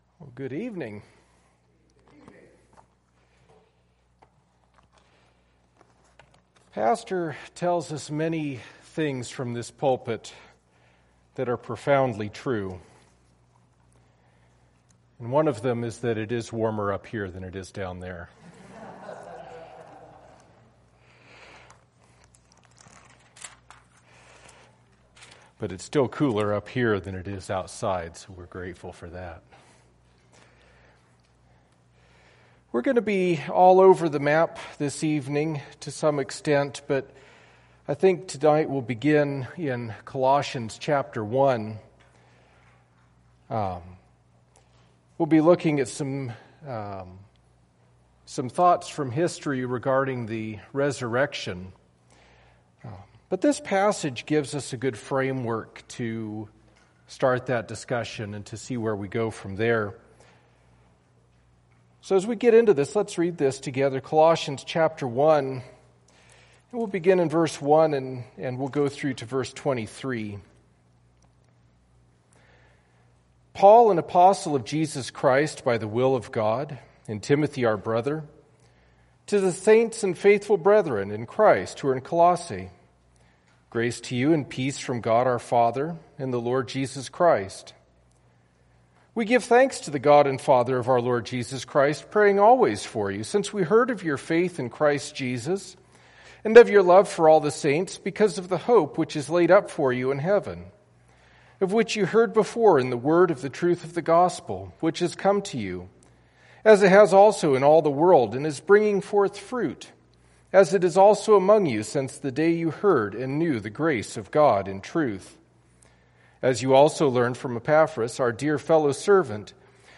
Colossians 1:1-29 Service Type: Sunday Evening « Be Faithful in the Small things